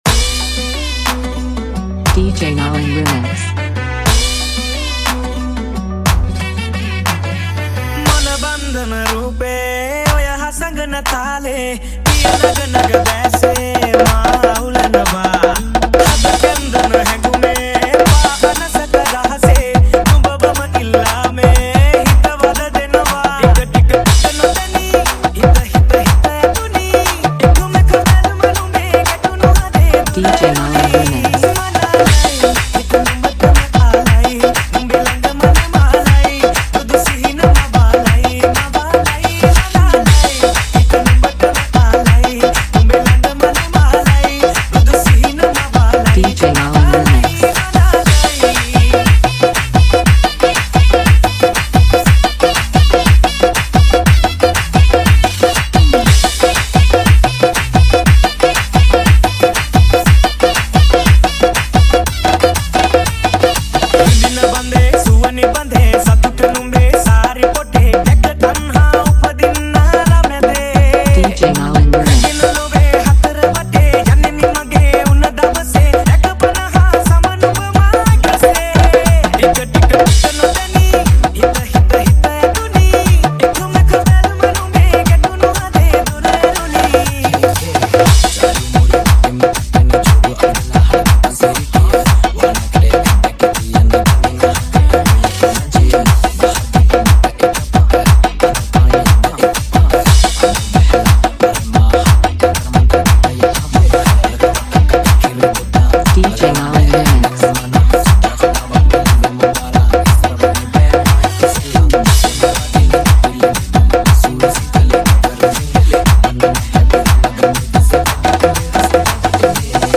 Remix New Song